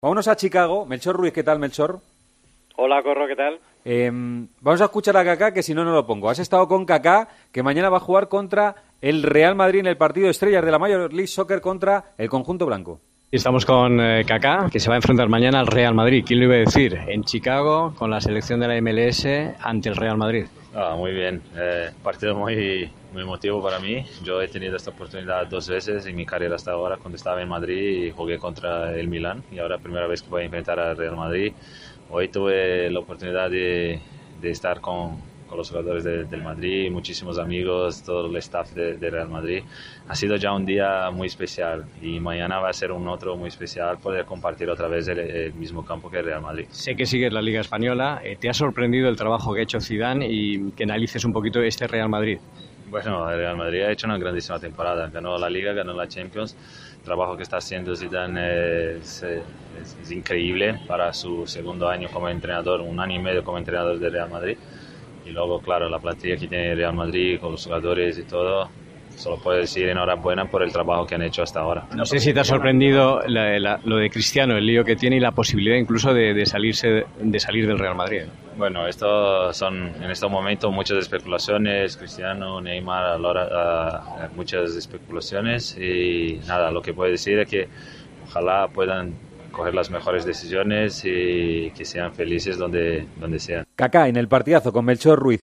El brasileño ha atendido en Chicago al enviado especial de la Cadena COPE